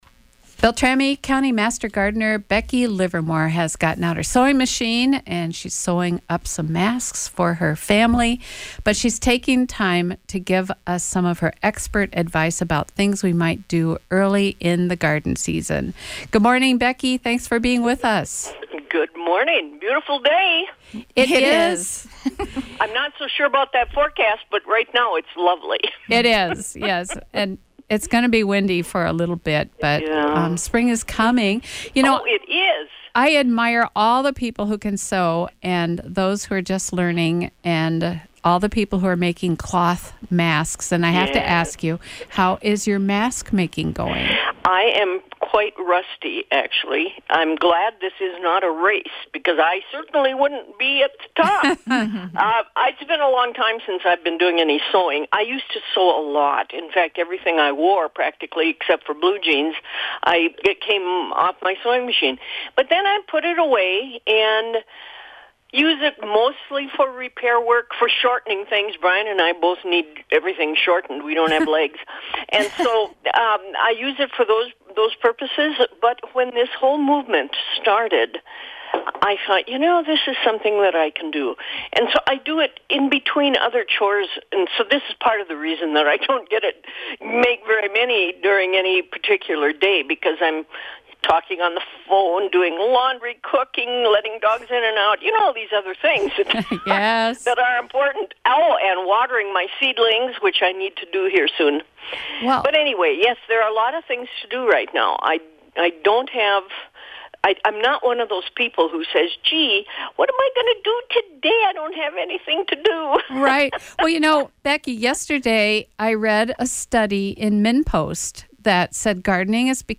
A couple things to remember, as you listen to her radio interview (below):